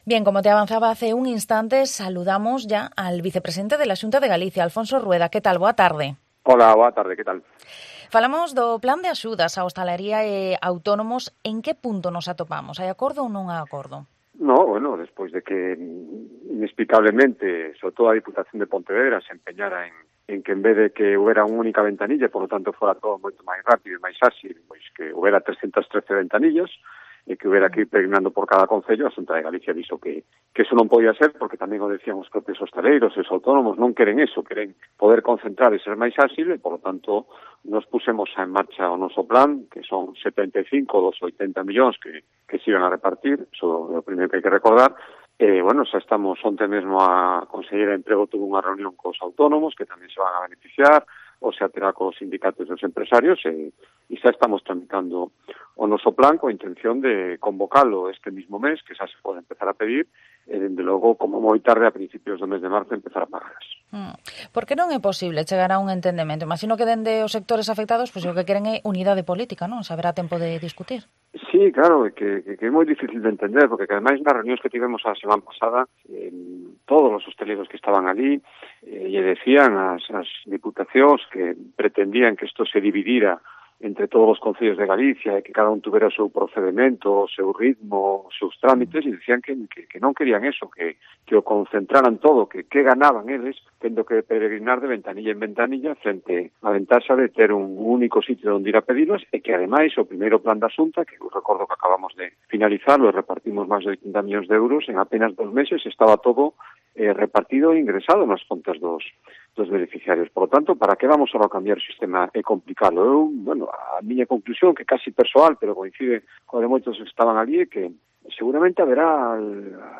Falamos con Vicepresidente da Xunta de Galicia, Alfonso Rueda, sobre o plan de rescate á hostalaría e autónomos. Tamén sobre a vacinación en Galicia. Escoita aquí a entrevista completa que lle fixemos en Mediodía Cope Vigo.